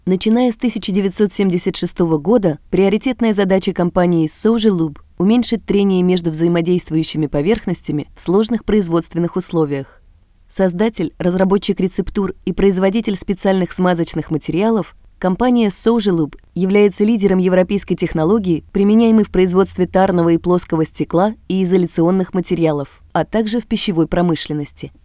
Native speaker of the standard dialect of Russian, from St. Petersburg.
Sprechprobe: Industrie (Muttersprache):